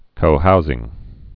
(kōhouzĭng)